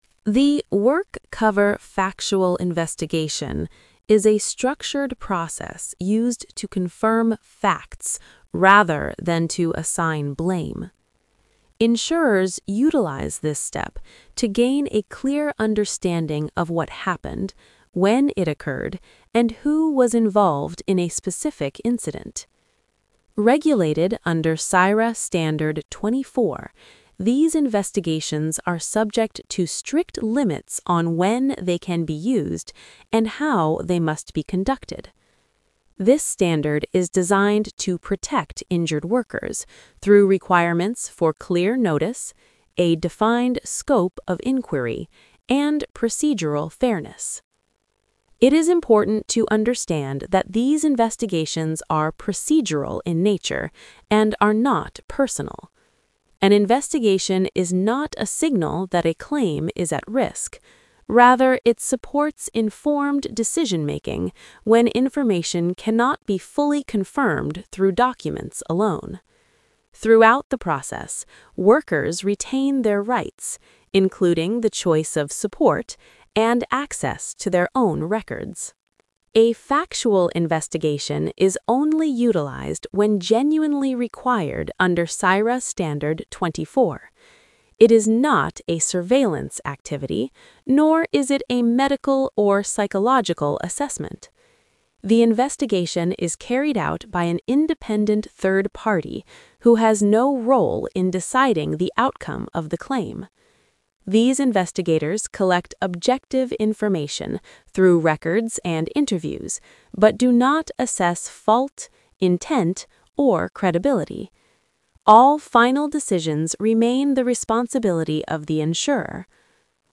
🎧 Listen to the audio version Single-host narration